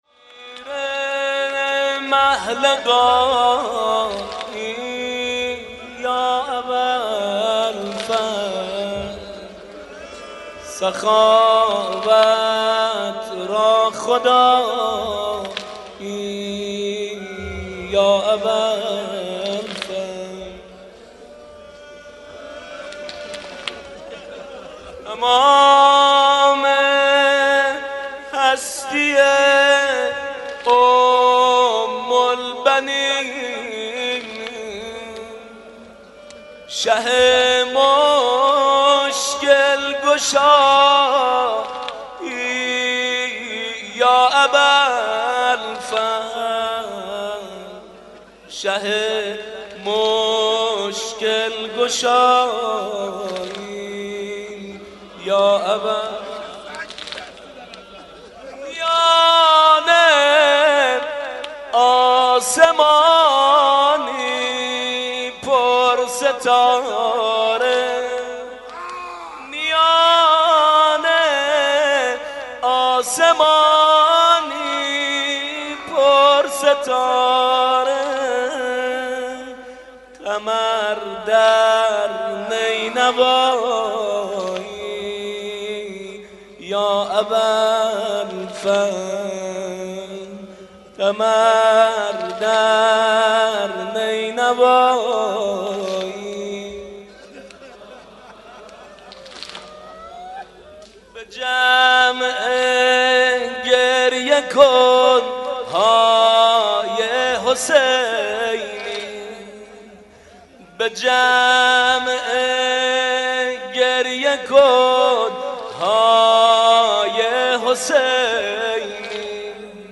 مناسبت : تاسوعای حسینی